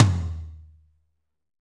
Index of /90_sSampleCDs/Northstar - Drumscapes Roland/DRM_Medium Rock/TOM_M_R Toms x